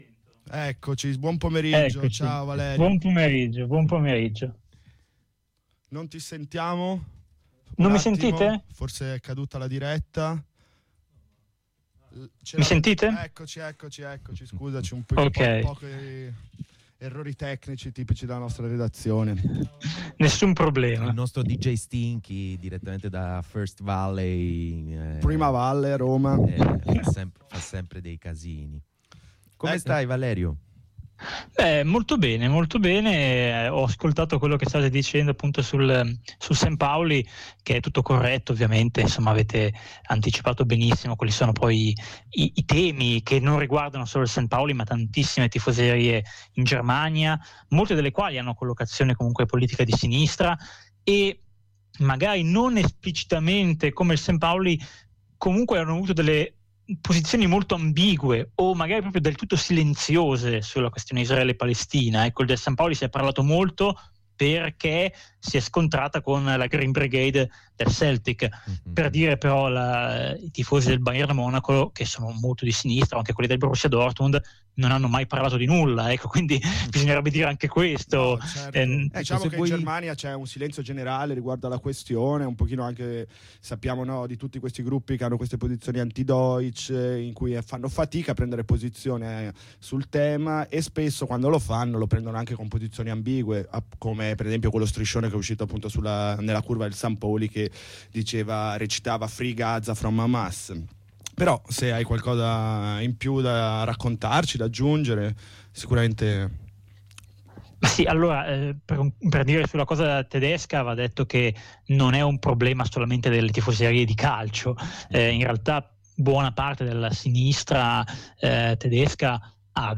Abbiamo esplorato il legame tra la questione palestinese e il mondo delle tifoserie, un tema che ha avuto un forte impatto sulle amicizie storiche tra gruppi di ultras. Attraverso interviste e approfondimenti, abbiamo cercato di comprendere come ideali e ideologie possano influenzare dinamiche interne ed esterne alle curve.